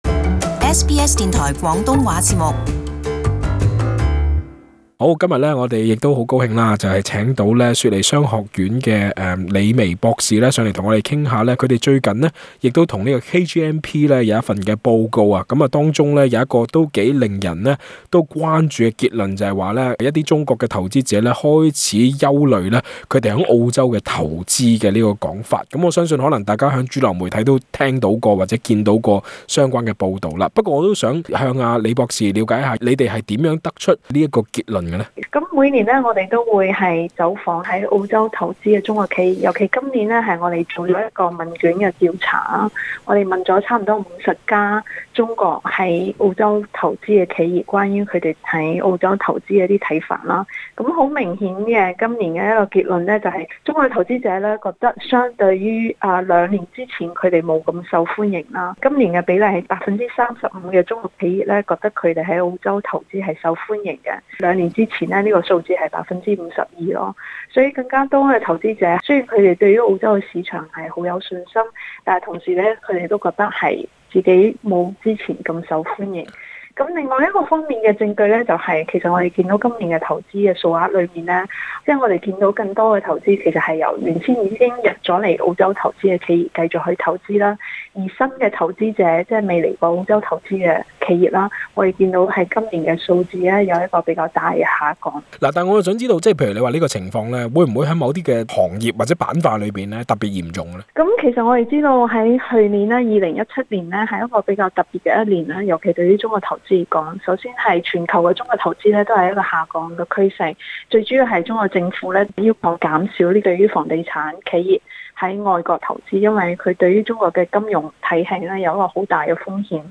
【特別專訪】中國投資者對澳洲感憂慮，本地商界要擔心嗎？